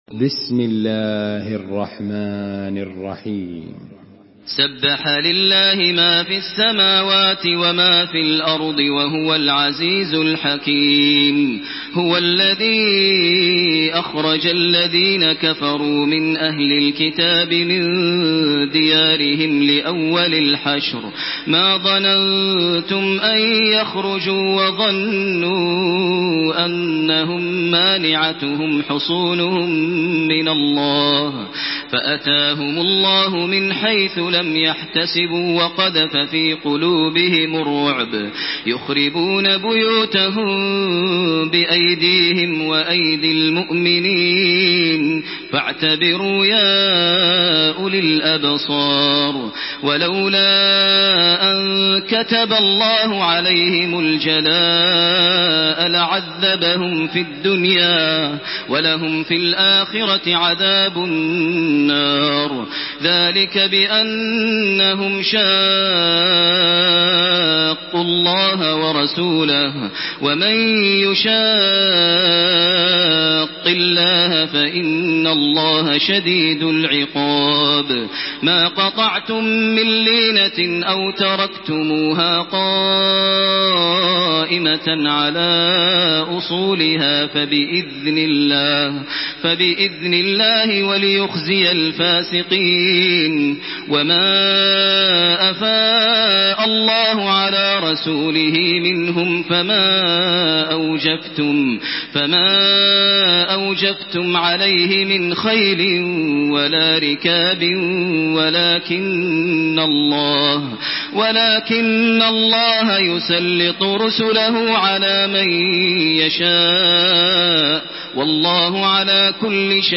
Makkah Taraweeh 1428
Murattal